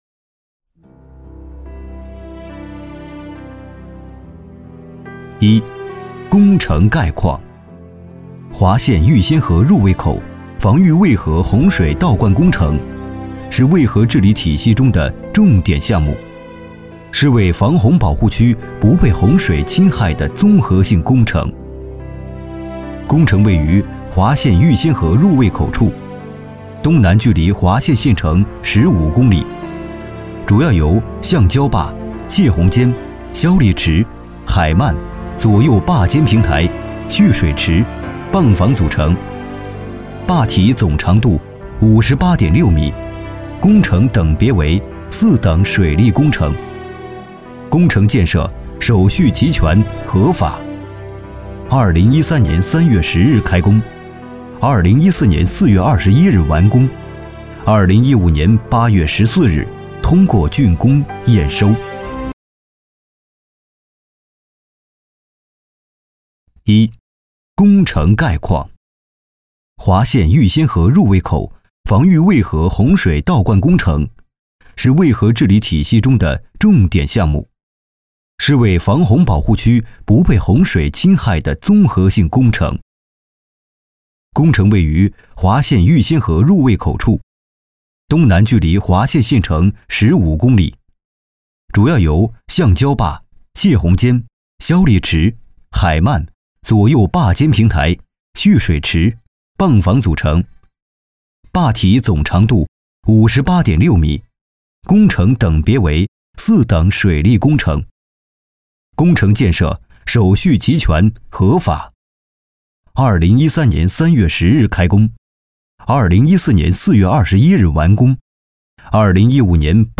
106男186系列-沉稳年轻
擅长：专题片 广告
特点：年轻稳重 年轻大气
风格:磁性配音